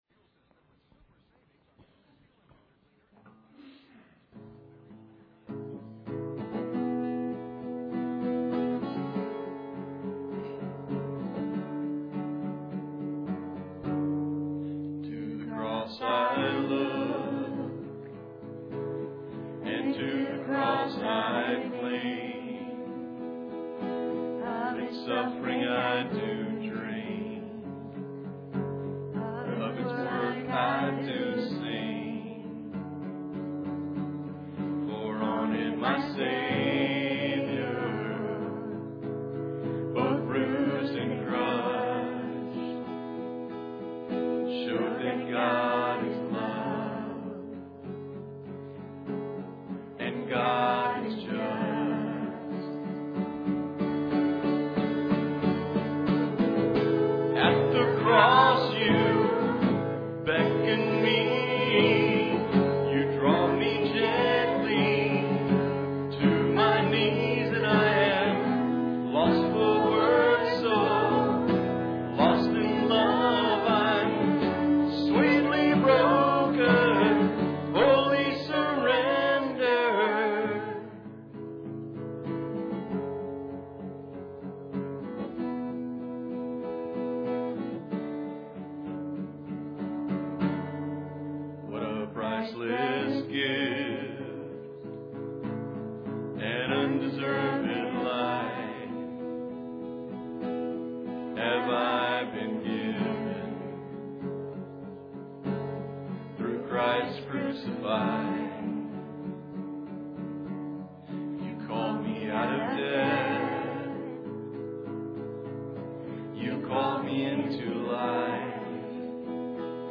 Passage: Philippians 3:3-9 Service Type: Sunday Service Guest speaker